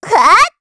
Estelle-Vox_Attack7_kr.wav